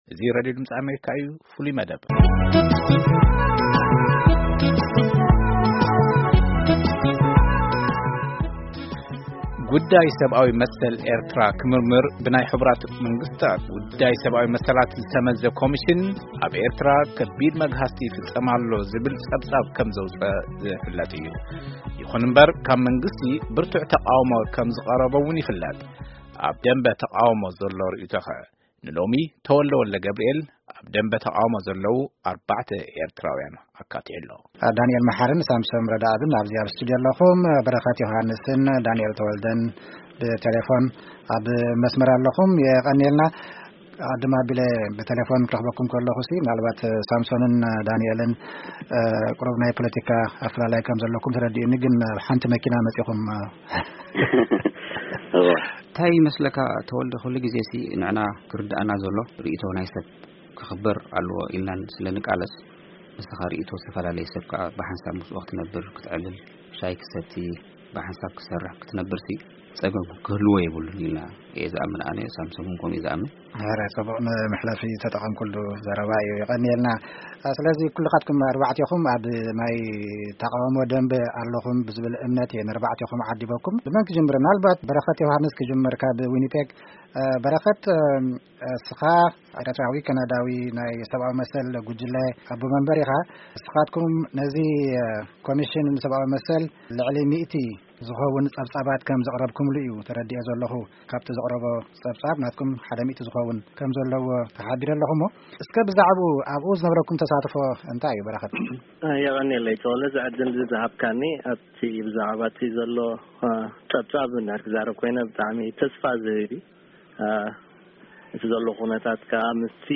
Eritrean Oppostion debate Human rights